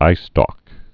(īstôk)